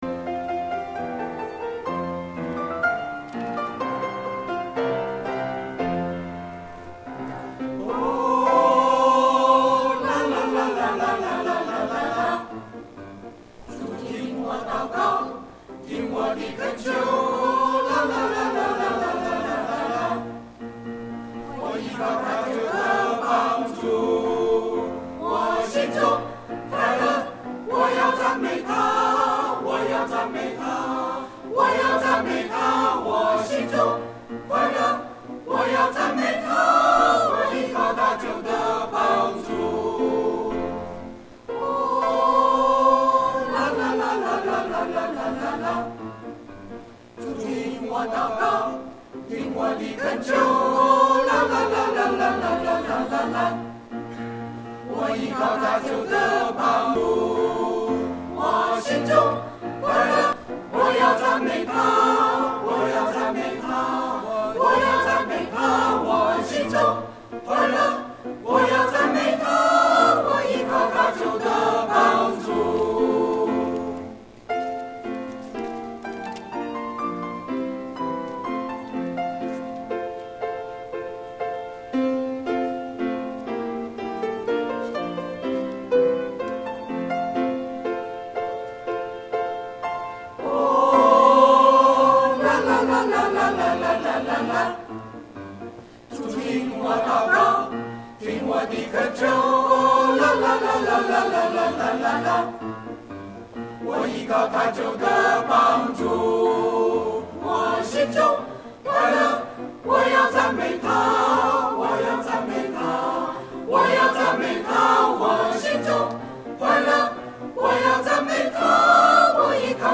• 詩班獻詩